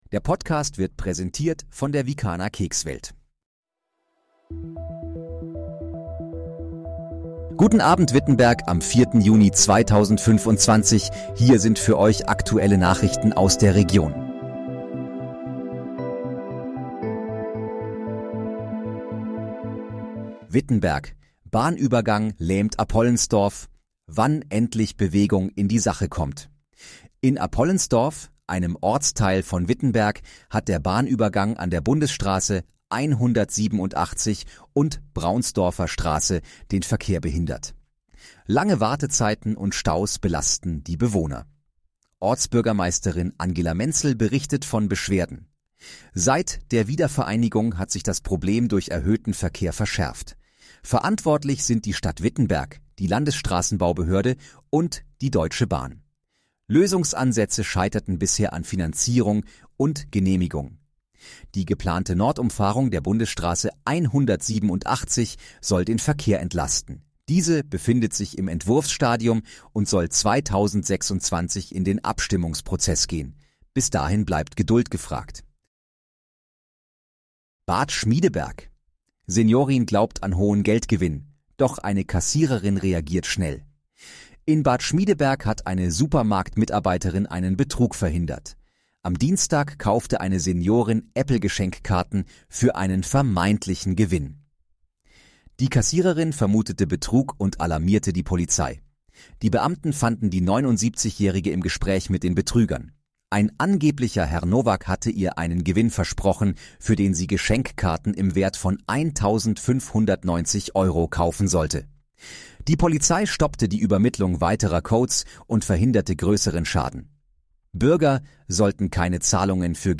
Guten Abend, Wittenberg: Aktuelle Nachrichten vom 04.06.2025, erstellt mit KI-Unterstützung
Nachrichten